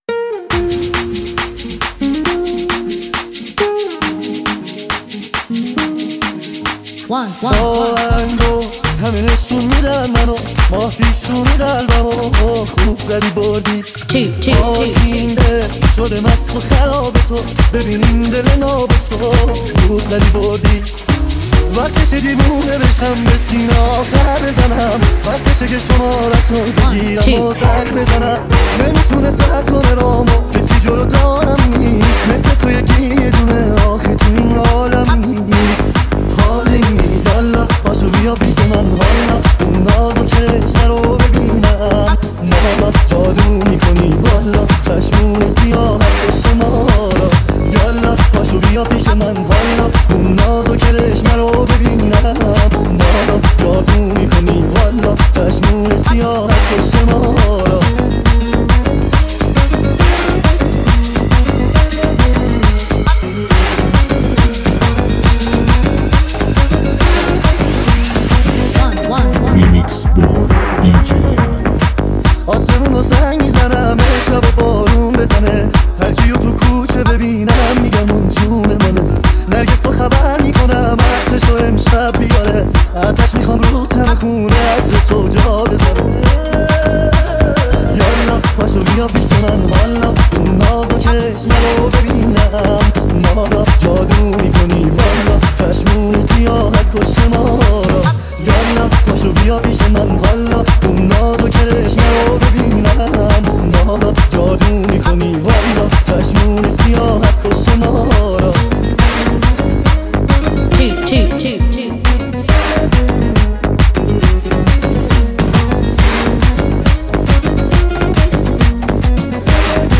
موسیقی شاد و پرانرژی برای شروع بهتر روزهای خود.